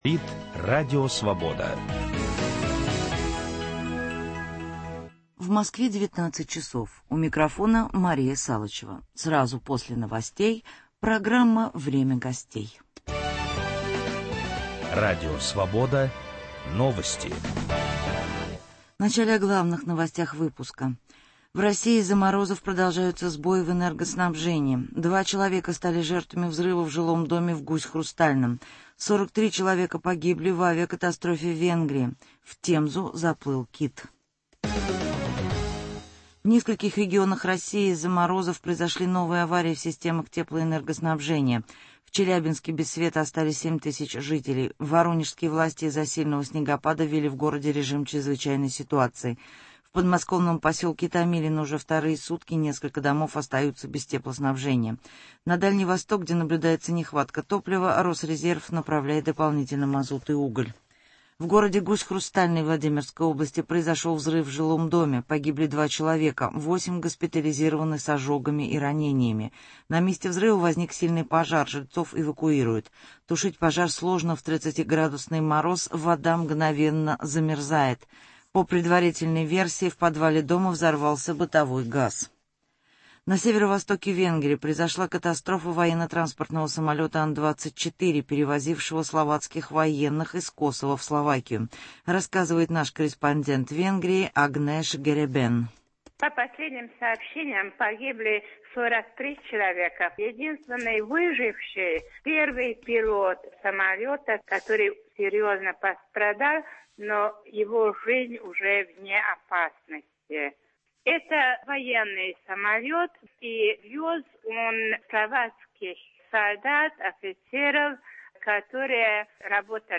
Известные и интересные люди ведут разговор о стране и мире, отвечают на вопросы в прямом эфире. Круг вопросов - политика, экономика, культура, права человека, социальные проблемы.